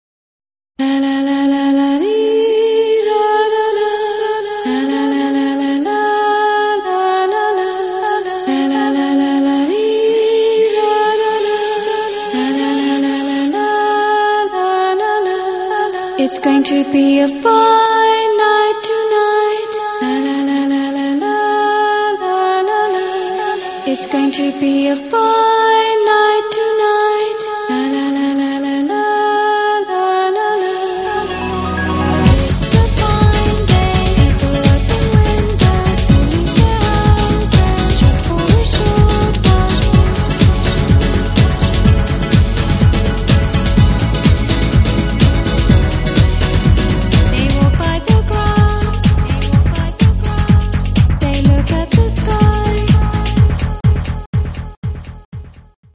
acappella mix